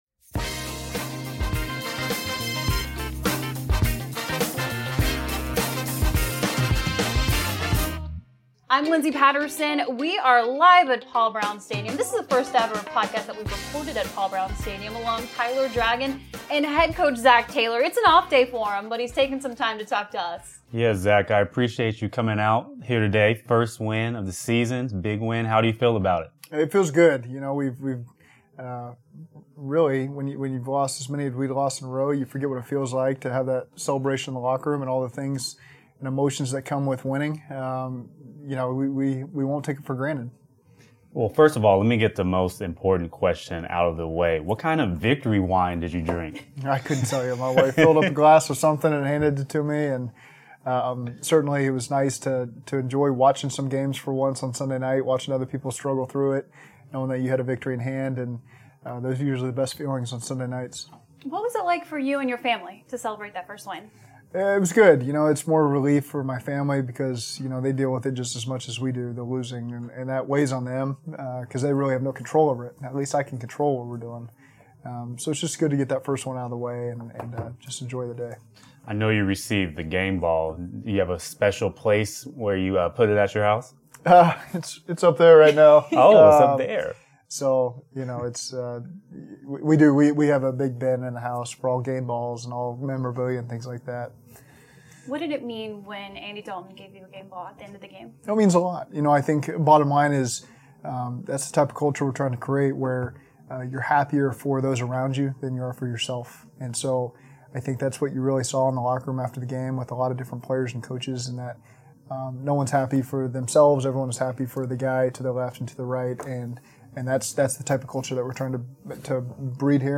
The Cincinnati Bengals got win No. 1 of the 2019 NFL season over the New York Jets in Week 13. After getting his first win, head coach Zac Taylor talks about what the win meant and what to look forward to in the last four weeks of the season